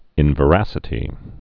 (ĭnvə-răsĭ-tē)